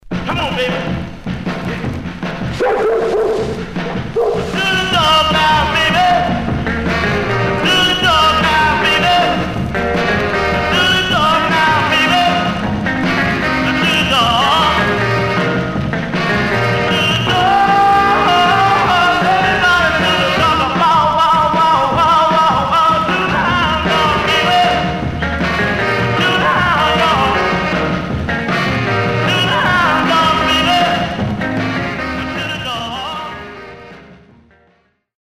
Stereo/mono Mono
Key 302 Categories: 45s, Rythm and Blues (ie.